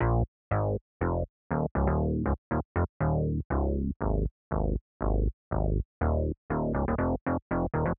17 Bass PT2.wav